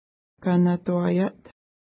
Pronunciation: ka:na:twa:ja:t
Pronunciation